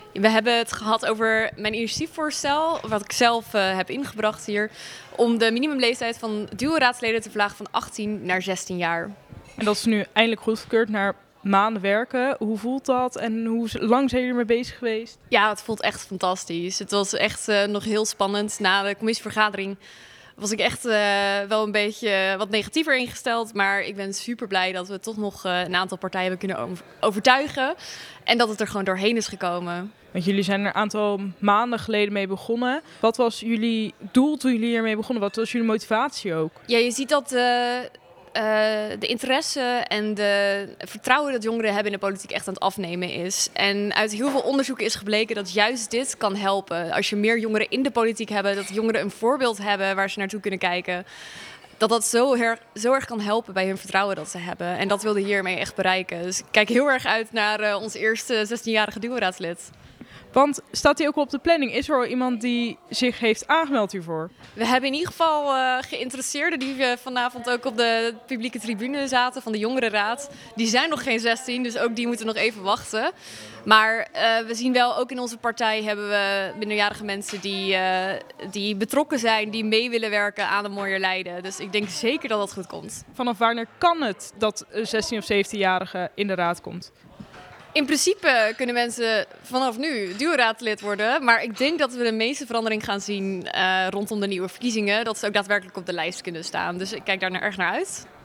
Verslaggever